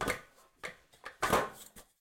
ladder1.ogg